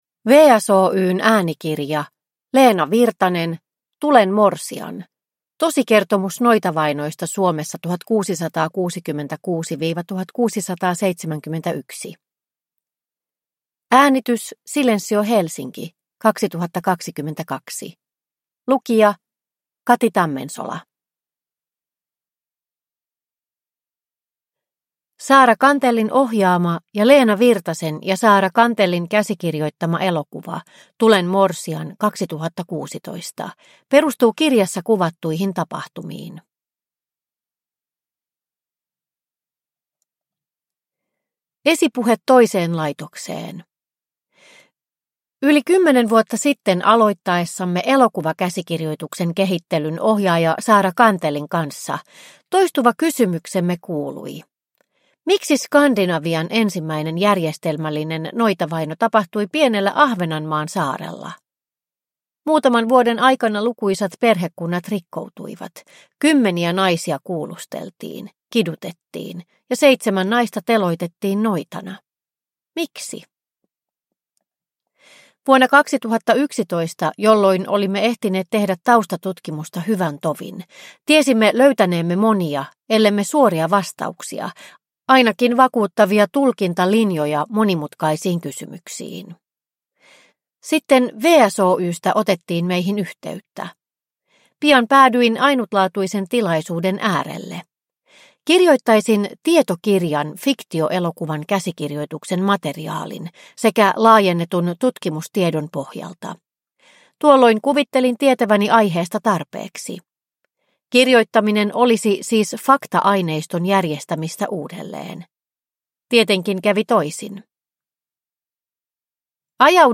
Tulen morsian. Tosikertomus noitavainosta Suomessa 1666-1671 – Ljudbok – Laddas ner